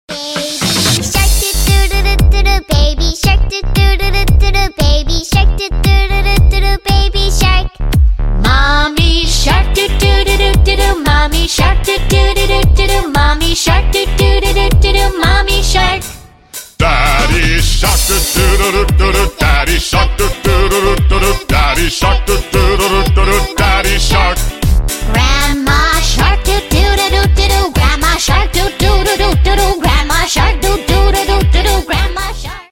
Веселая детская песня про членов акульей семьи